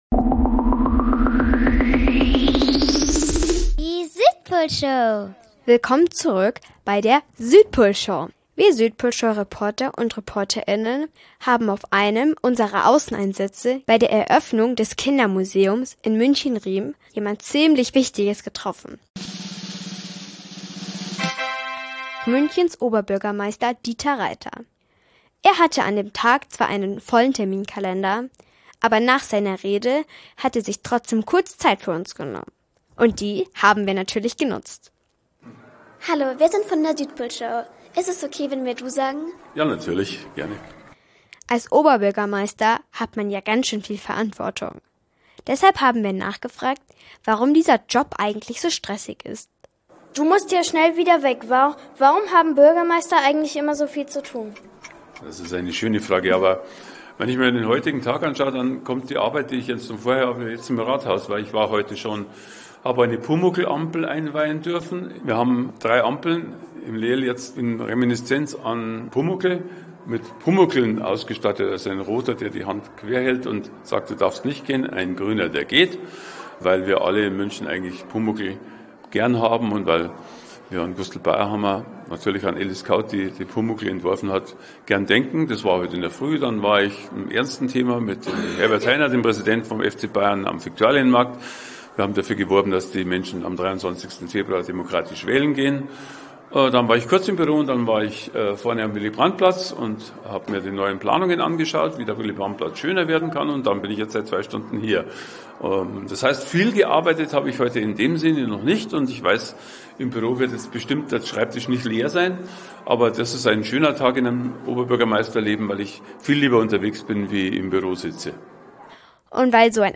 Interview mit Dieter Reiter │Eröffnung Kindermuseum
auf der Eröffnungsfeier des Kindermuseums getroffen und zu seinem